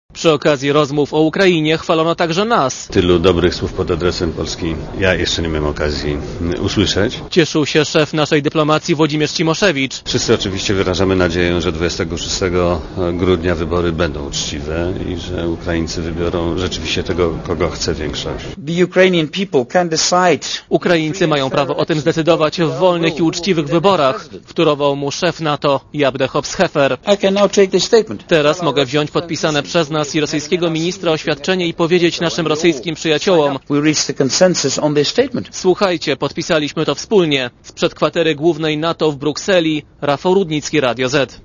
Korespondencja z Brukseli